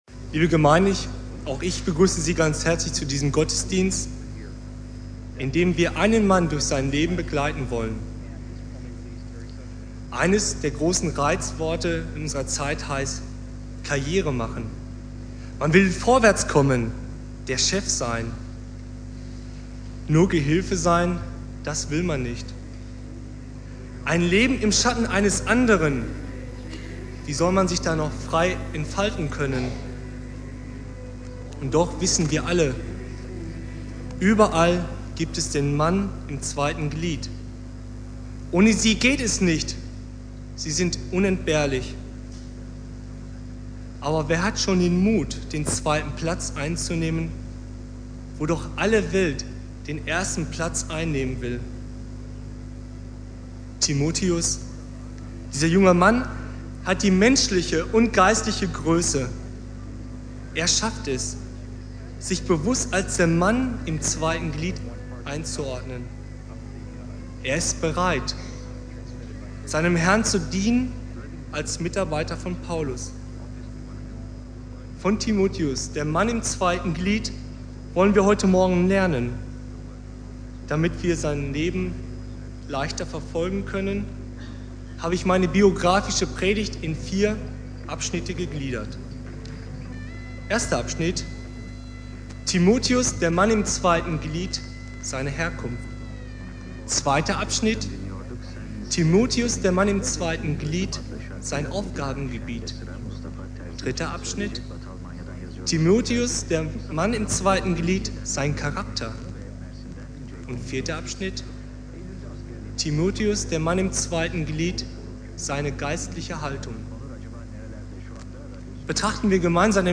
Relativ schlechte Aufnahmequalität - die Stimmen im Hintergrund stammen von einem Mittelwellen-Radiosender, der durch ein nicht gut abgeschirmtes Mikrofonkabel hörbar wurde. Predigtreihe: Themenpredigten Dauer: 22:53 Abspielen: Ihr Browser unterstützt das Audio-Element nicht.